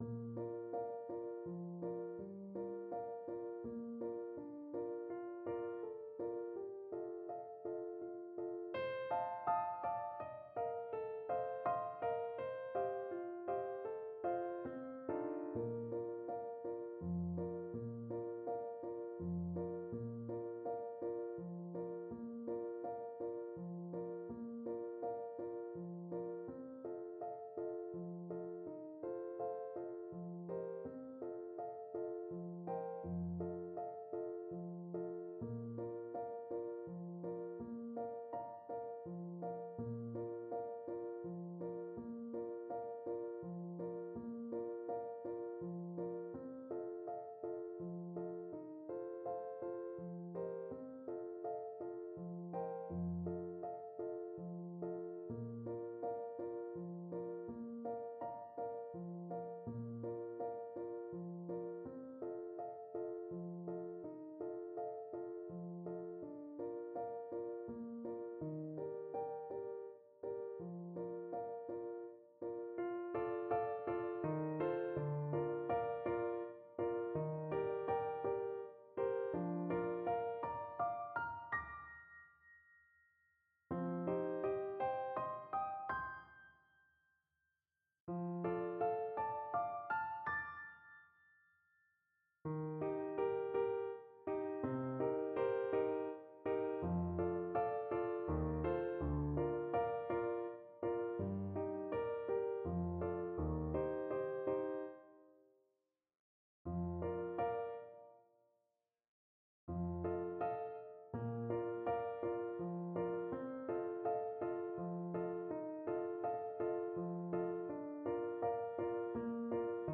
3/4 (View more 3/4 Music)
F4-Eb7
Allegretto =116 Allegretto =120
Classical (View more Classical Clarinet Music)